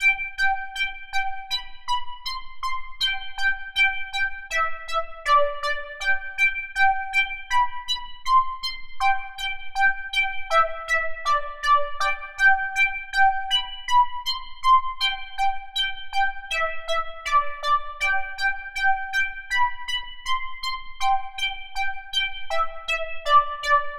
Bouncy Synth 80 Bpm .wav